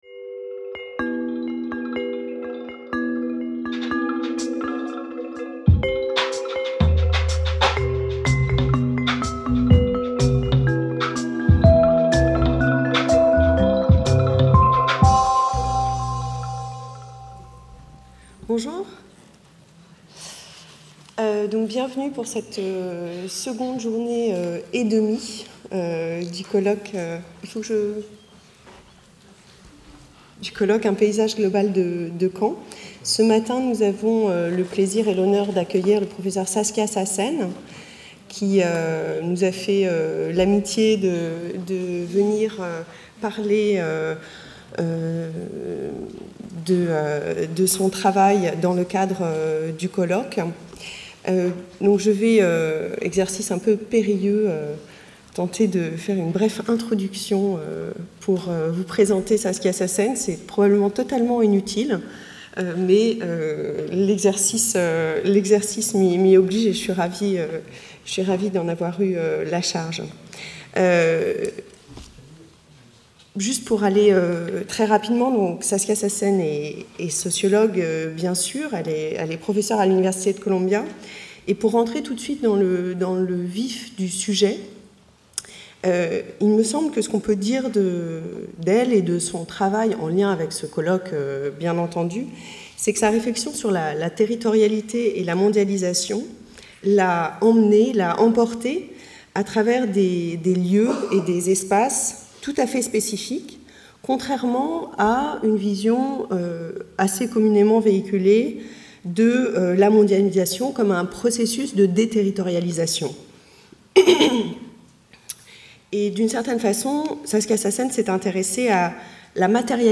8- Conférence de Saskia Sassen, professeur de sociologie à Columbia University (New York) | Canal U